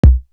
Professional Kick.wav